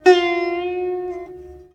SITAR LINE60.wav